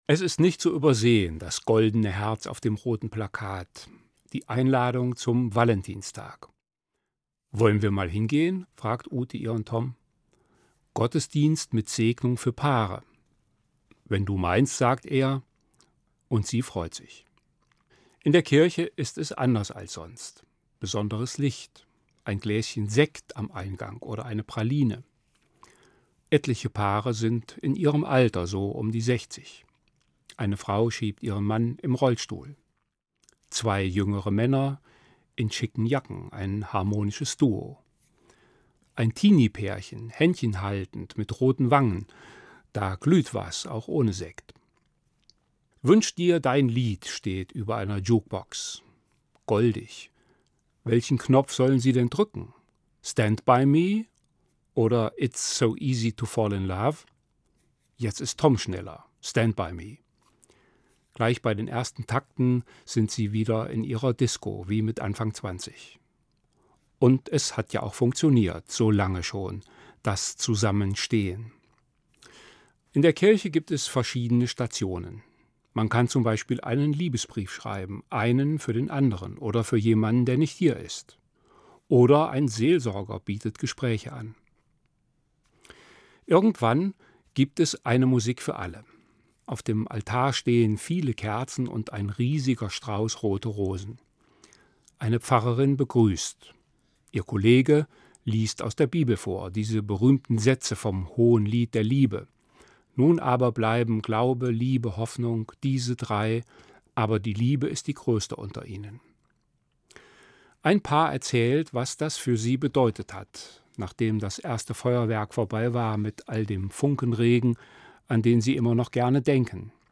Evangelischer Pfarrer, Marburg